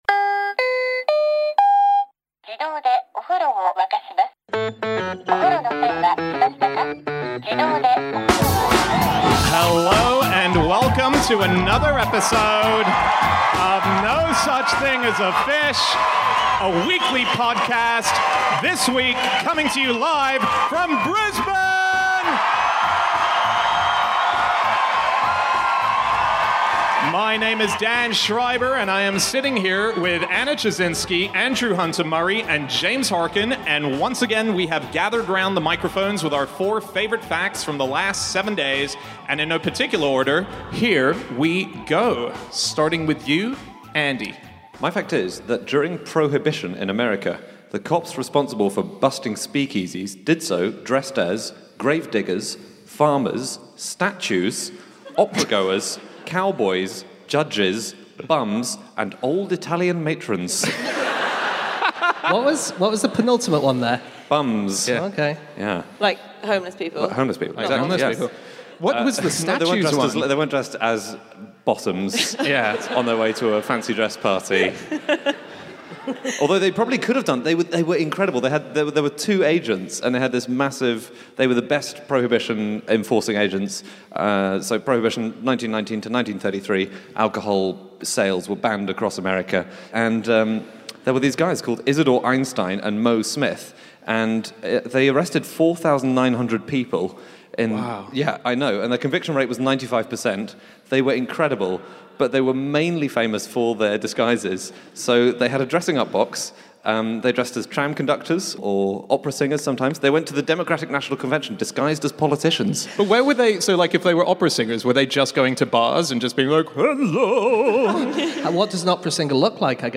Live from Brisbane